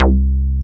SYNTH BASS-2 0001.wav